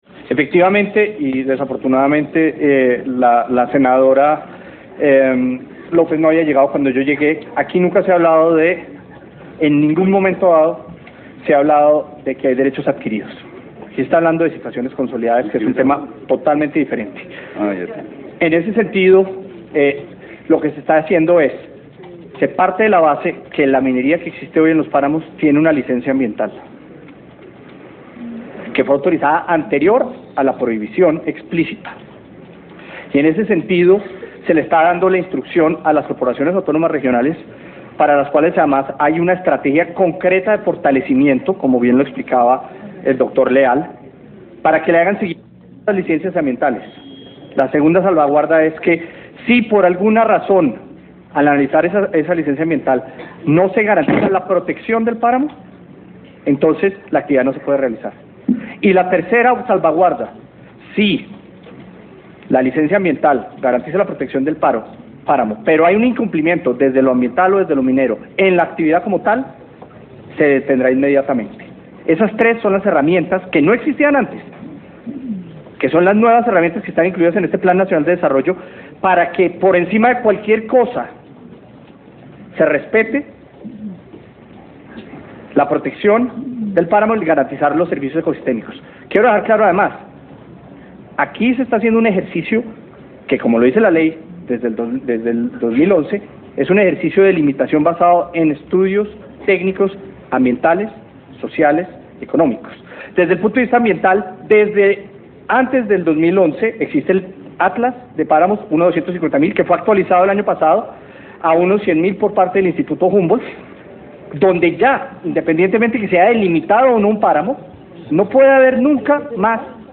Declaraciones del Viceministro de Ambiente y Desarrollo Sostenible, Pablo Vieira Samper